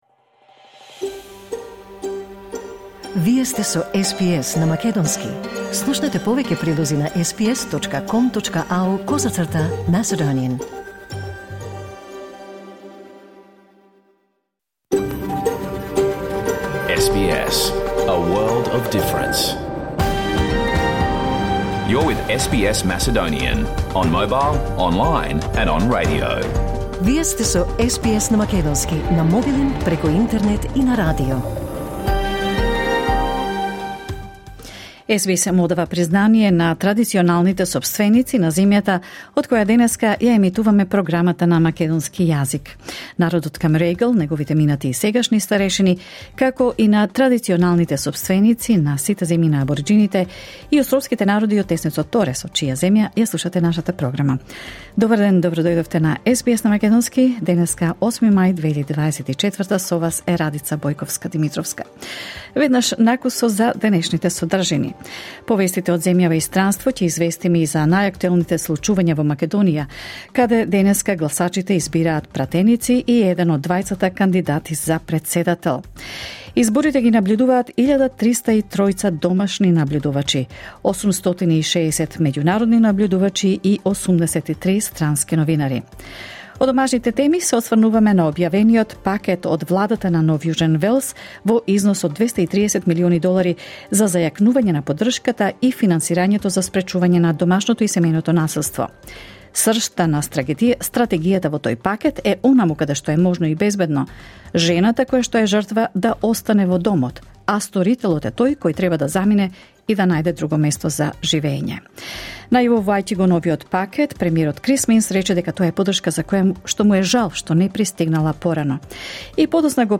SBS Macedonian Program Live on Air 8 May 2024